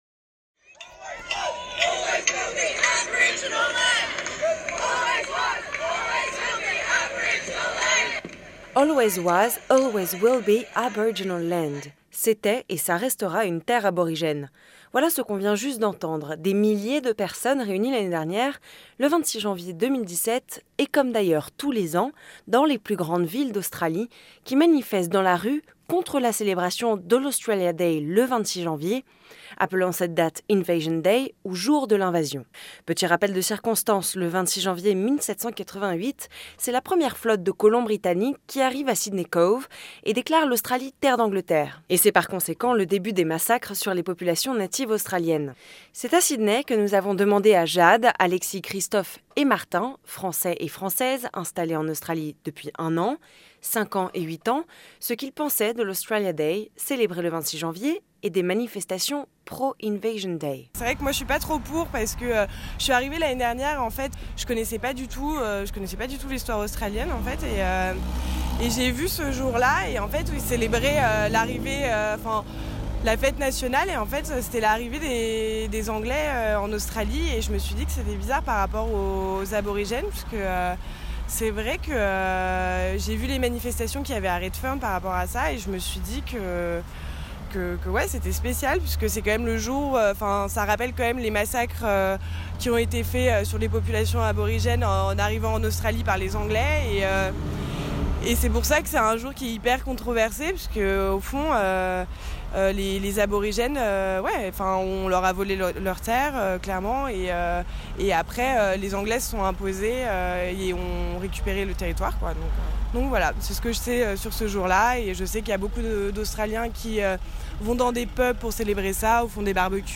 We have been interviewing four French citizen living in Australia and asked them what they think of Australia Day being celebrated on January 26th.